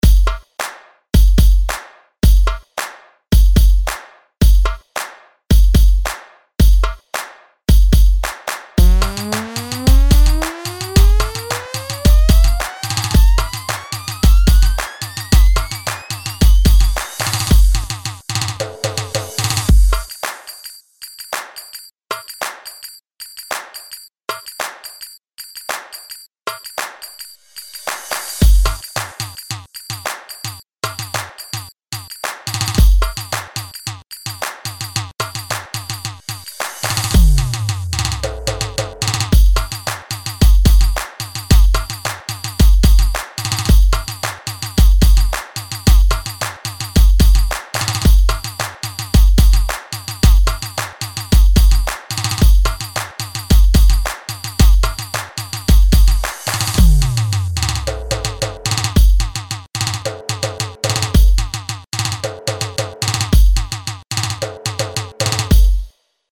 110 Bpm Beat